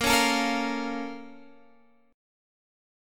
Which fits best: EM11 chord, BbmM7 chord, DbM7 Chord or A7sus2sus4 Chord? BbmM7 chord